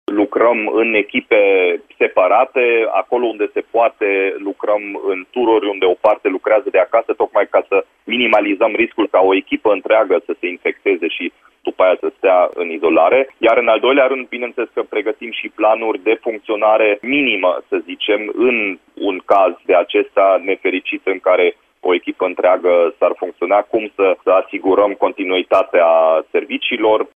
Primarul Dominic Fritz a precizat că măsura este necesară în condițiile în care răspândirea tulpinei Omicron a virusului SARS COV 2 devine una comunitară. El a precizat la Radio Timișoara că, doar prin impunerea unui program strict, activitatea primăriei nu va fi afectată.